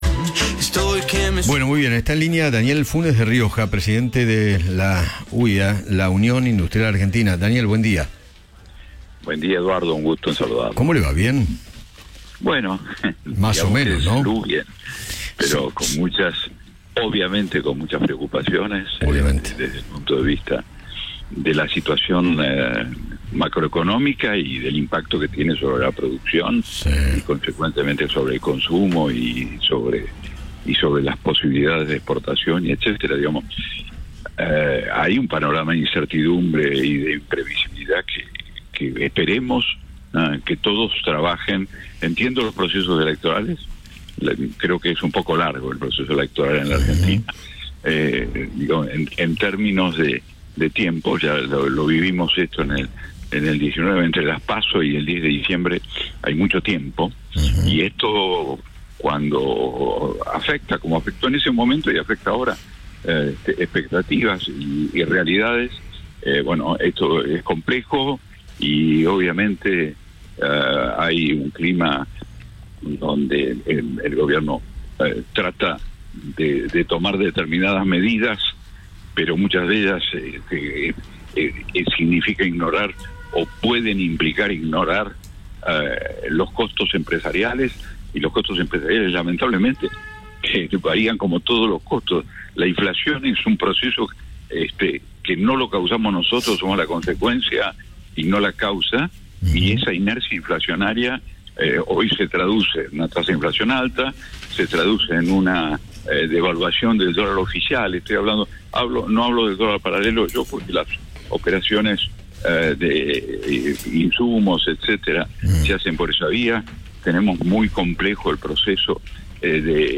Daniel Funes de Rioja, presidente de la Unión Industrial Argentina (UIA), conversó con Eduardo Feinmann sobre el impacto de la devaluación en la economía nacional.